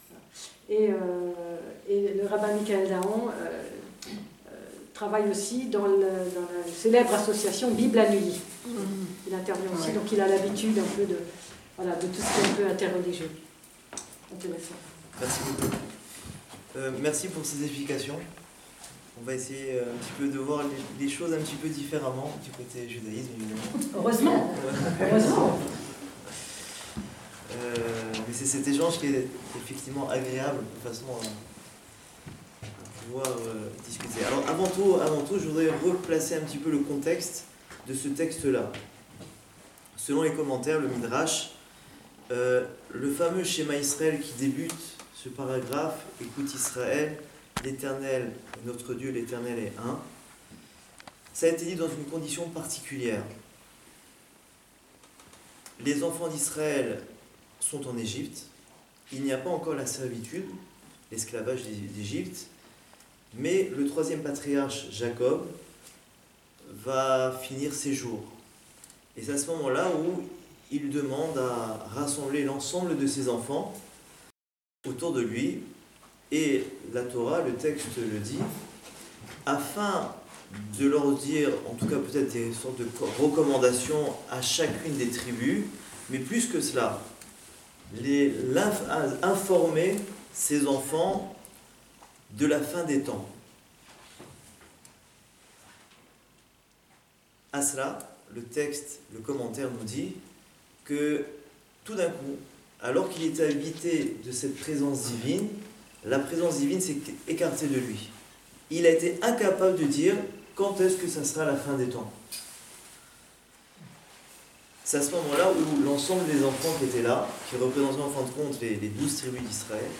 Les échanges avec la salle portent sur la présence de Dieu dans le monde et au cœur de chaque être humain, et sur la façon dont l'homme peut se tourner ou retourner vers Lui.